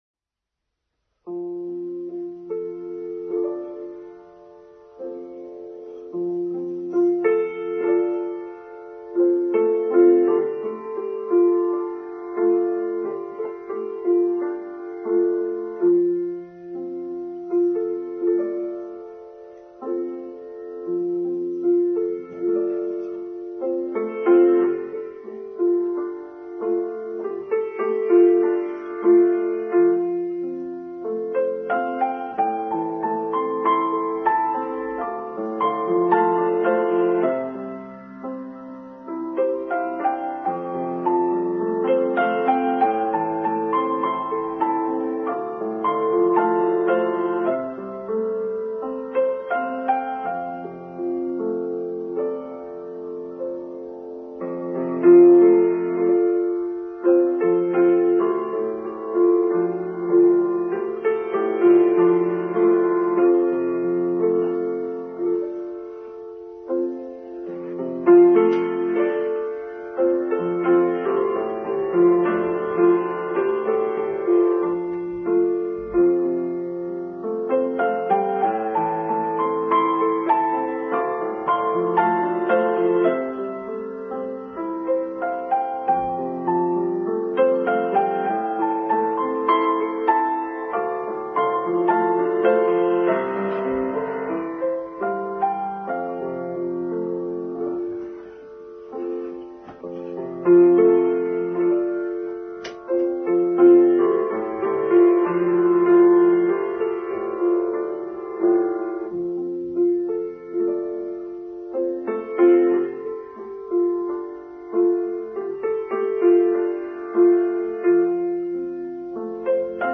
Resilience and Hope: Online service for Sunday 28th February 2021